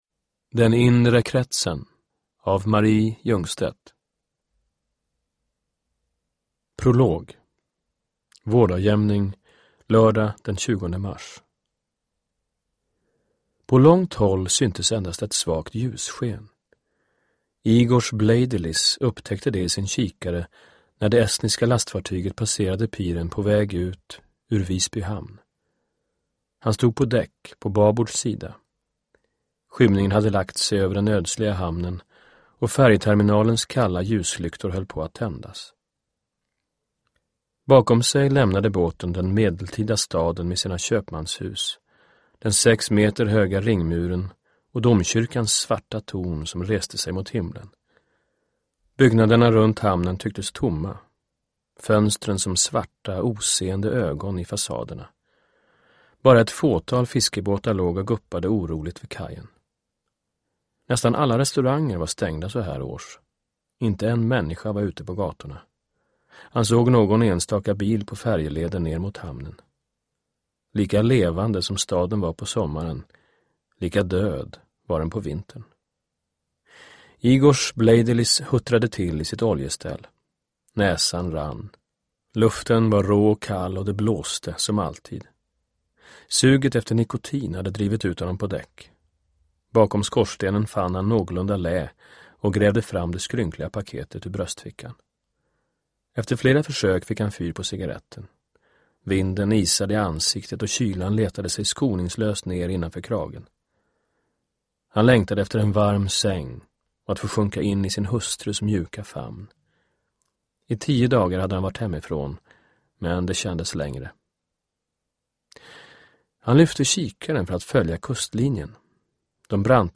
Uppläsare: Anders Ekborg
Ljudbok